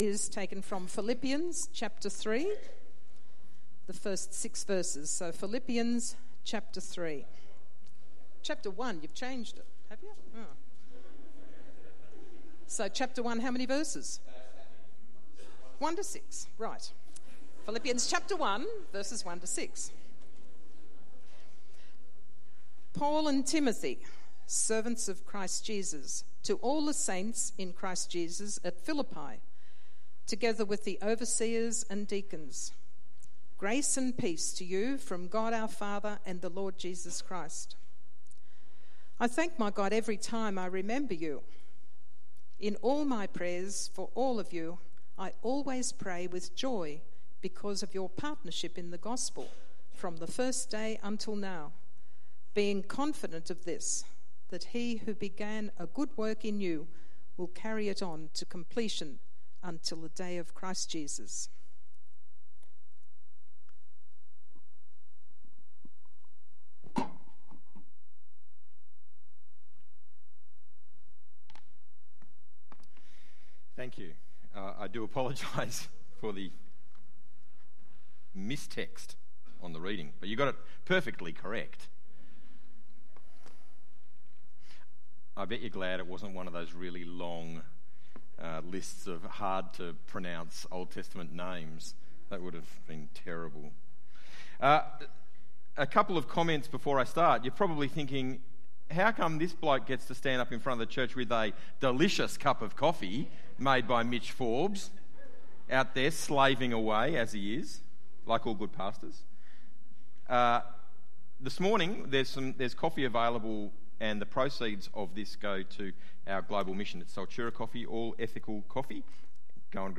Bible Text: Philippians 3:1-14 | Preacher